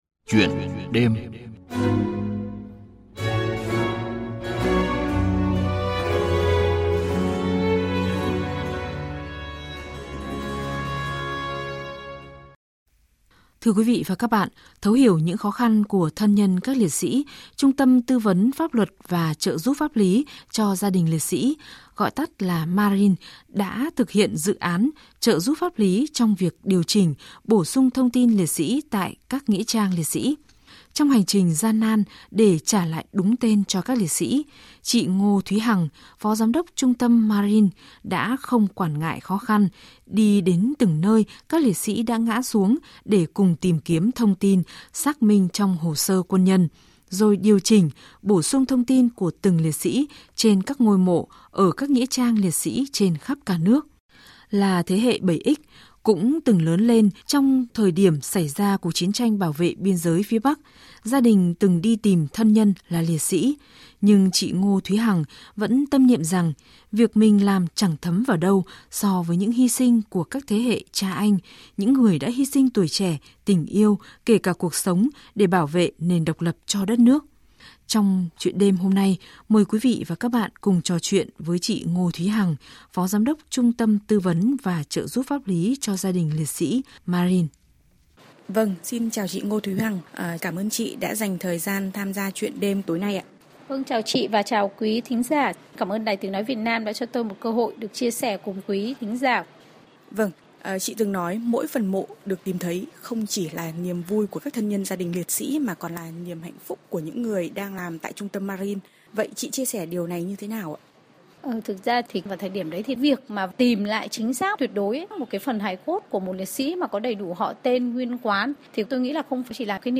Trò chuyện